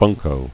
[bun·ko]